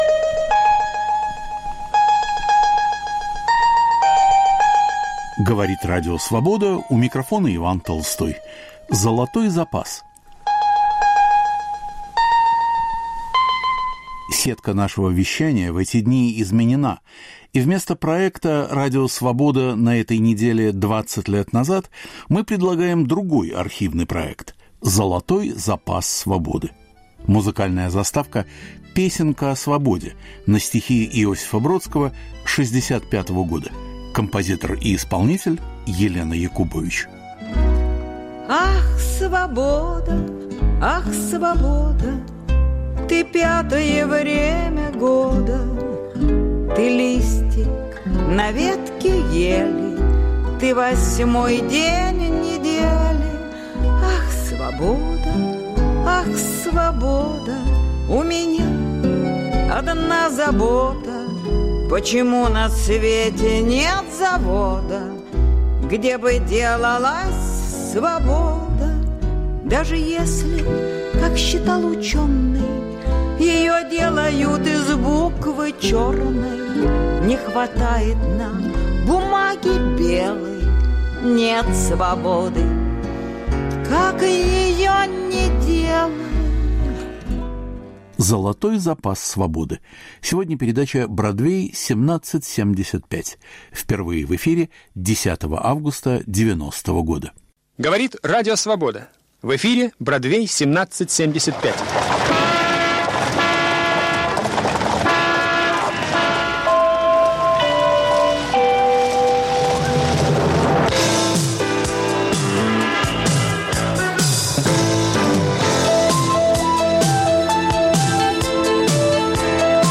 Передача "Бродвей 1775". Впервые в эфире 10 августа 1990.